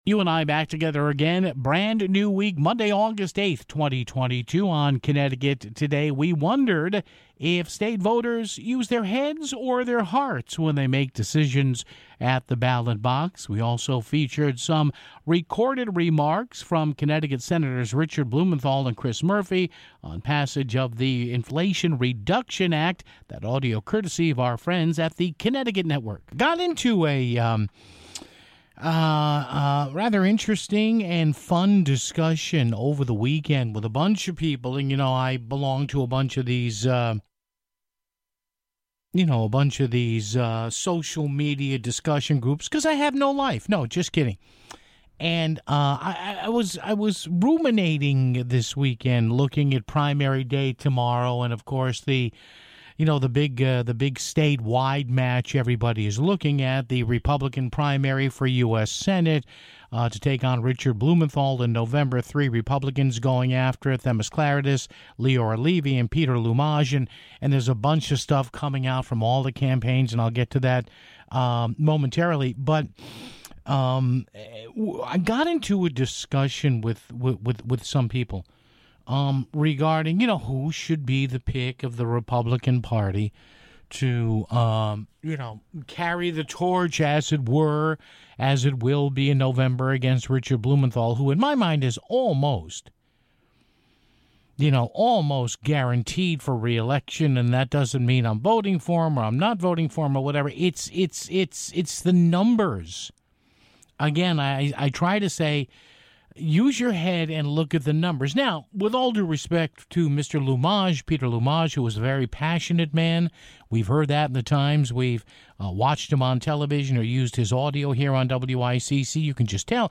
We also featured recorded remarks from Connecticut Senators Richard Blumenthal and Chris Murphy on passage of the Inflation Reduction Act (9:28).